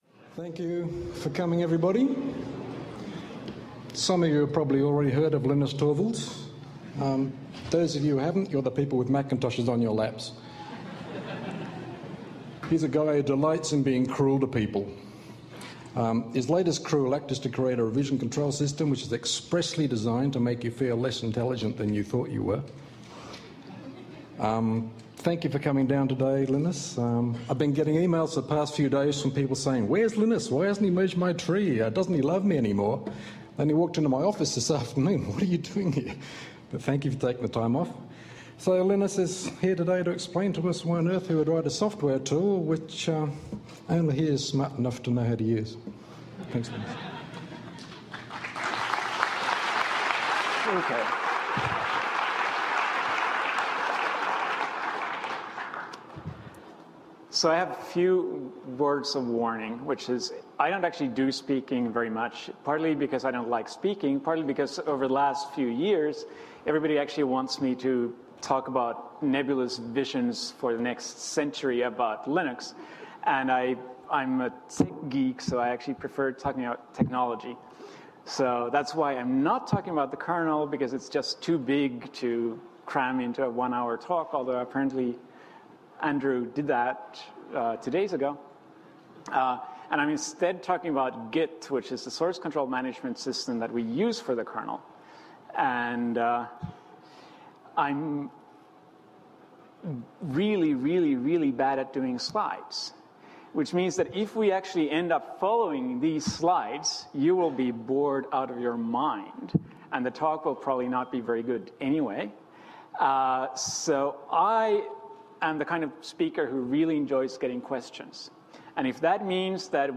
Two years later, Torvalds is invited to speak at Google Tech Talks. He explains the personal, social and technical stories of how the design principles of “Git” emerged in the first place and answers many questions by the programmers of Google Inc.. His talk contains a lot of humourous banter within the Free and Open Source Communites.